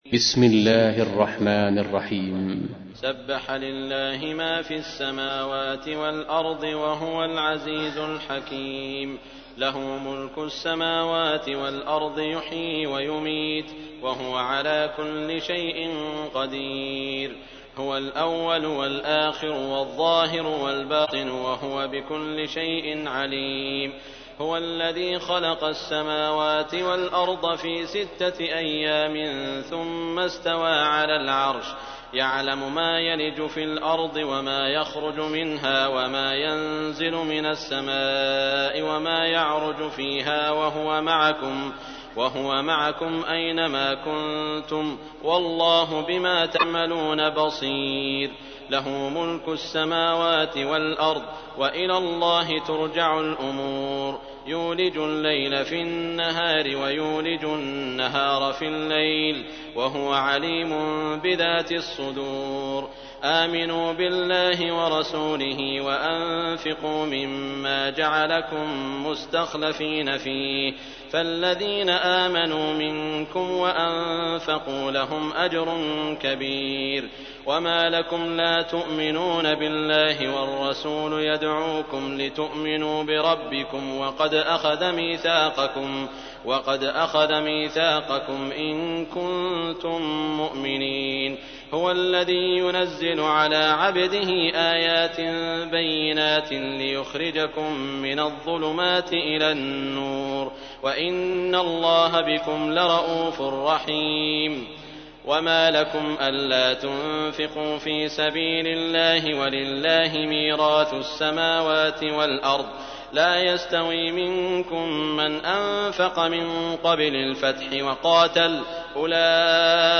تحميل : 57. سورة الحديد / القارئ سعود الشريم / القرآن الكريم / موقع يا حسين